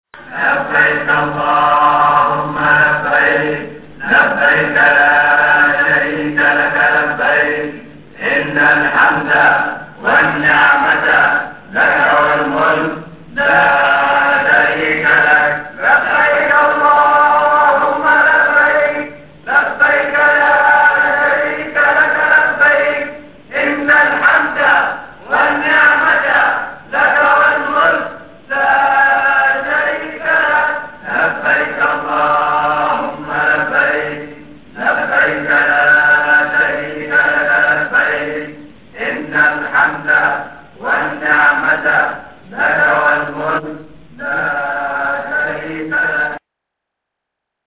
talbiyah.mp3